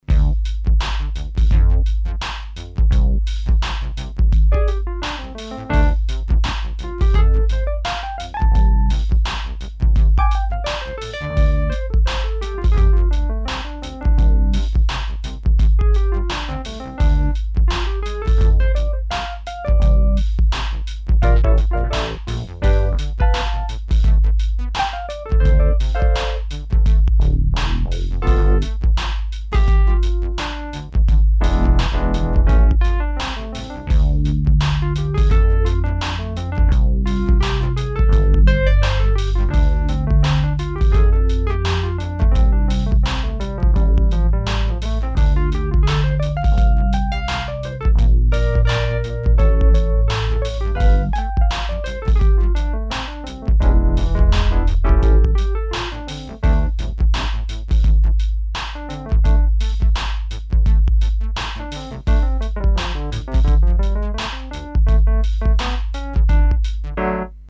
Random noodling while hunting for musical insight has never been so much fun.